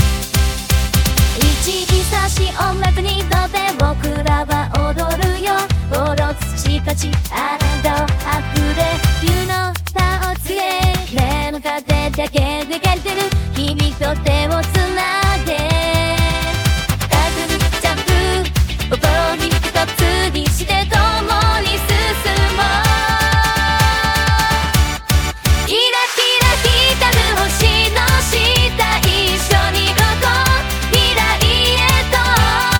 リミックスした楽曲がこちらです。